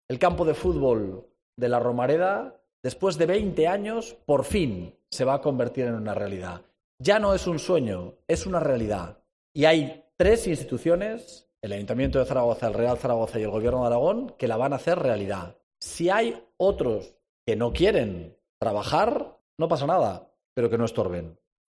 El presidente Jorge Azcón explica la importancia de la sociedad que impulsará la nueva Romareda.